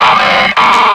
Cri de Tengalice dans Pokémon X et Y.